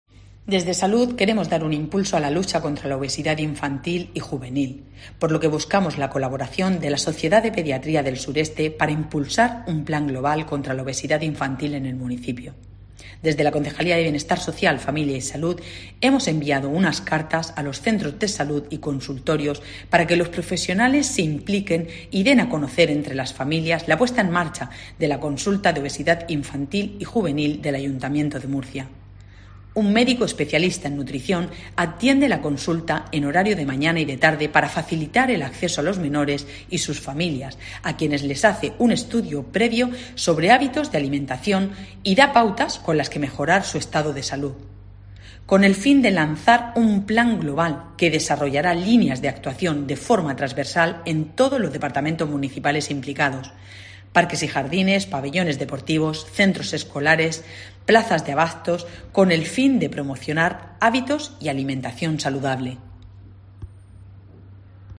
Pilar Torres, concejala de Bienestar Social, Familia y Salud